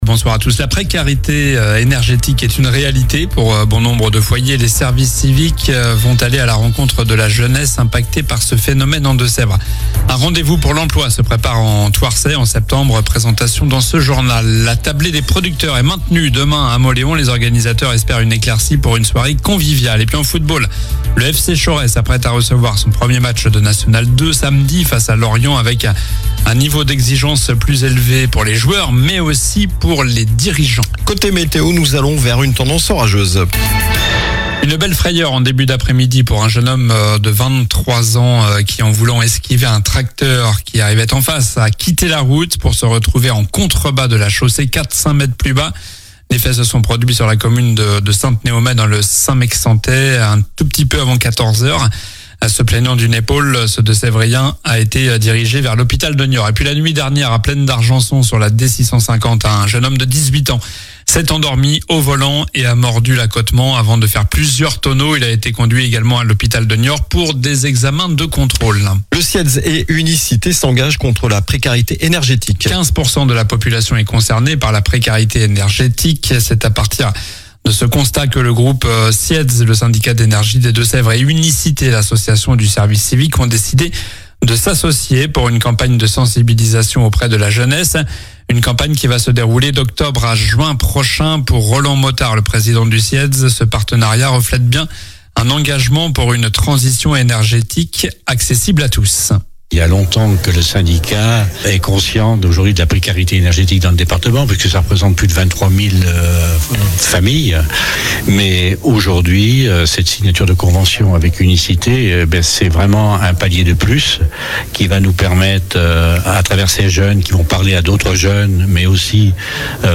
Journal du jeudi 28 août (soir)